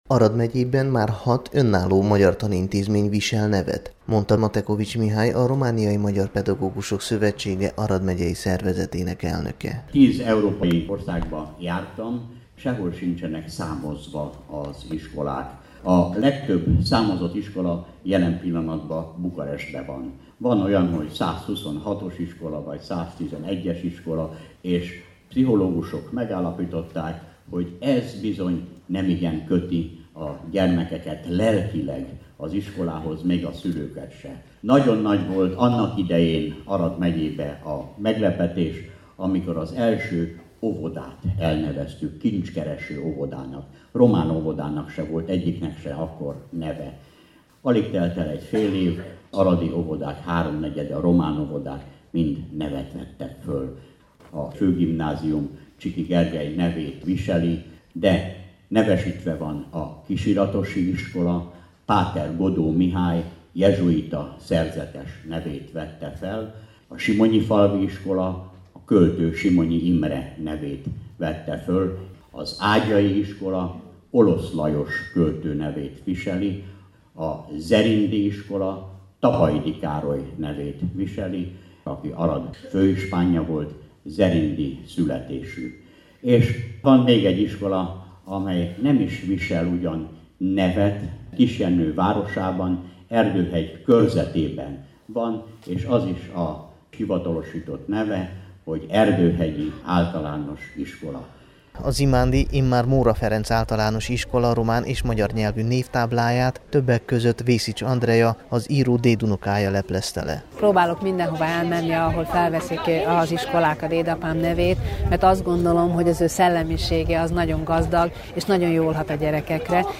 A szombati ünnepségről